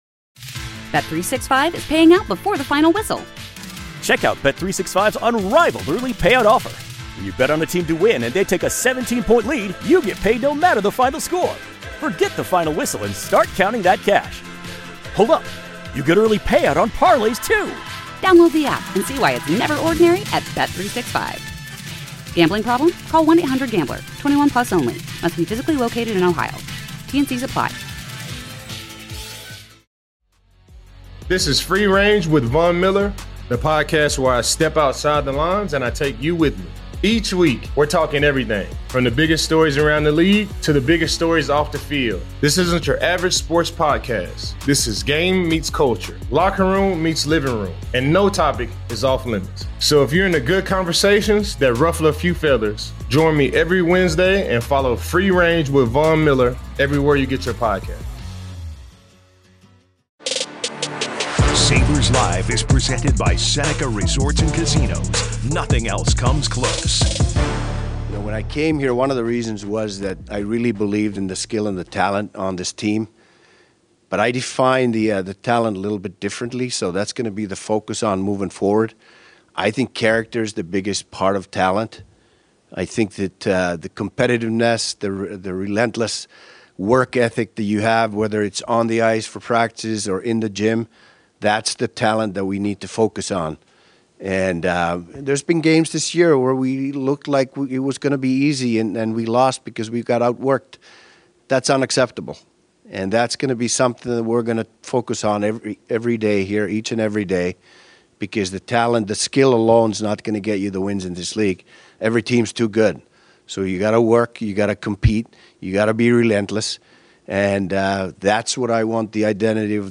Heard daily from 12PM-1PM on WGR and simulcast on MSG TV, Sabres Live goes deep into the corners everyday, breaking down the play and the players of every Sabres game. Plus, we take listeners around the NHL getting the inside scoop on all the top stories.